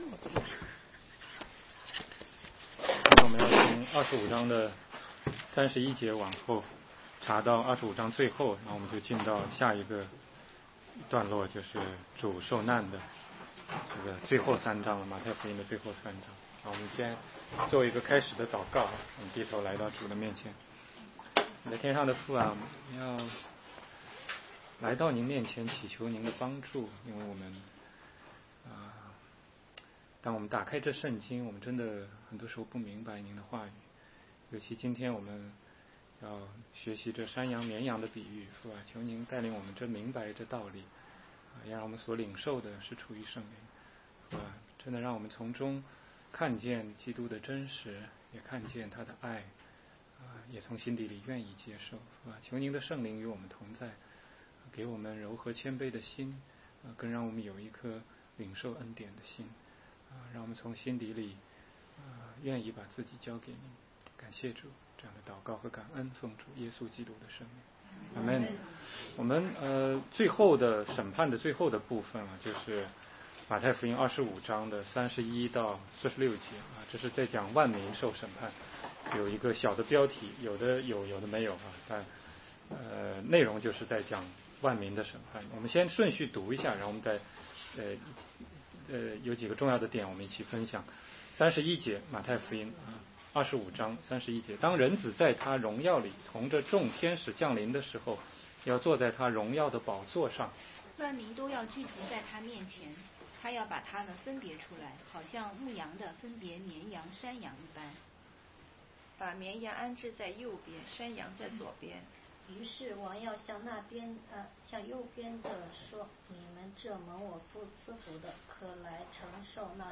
16街讲道录音 - 马太福音25章31节-26章13节